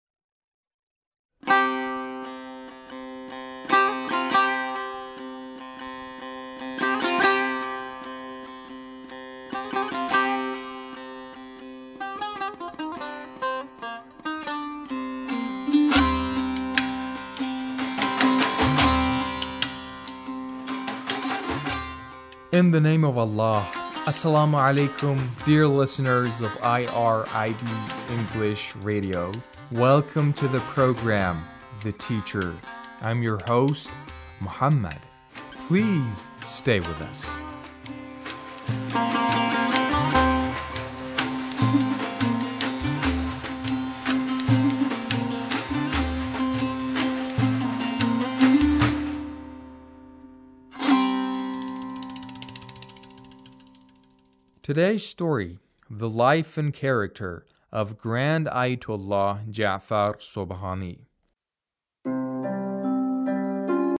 A radio documentary on the life of Ayatullah Sobhani